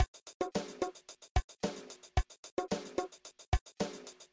Demo music for download
The tracks are identical in length, but have a different amount of instruments playing.
The drum track will be playing.
drums.ogg